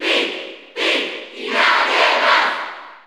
Category: Pit (SSBU) Category: Crowd cheers (SSBU) You cannot overwrite this file.
Pit_Cheer_Spanish_NTSC_SSB4_SSBU.ogg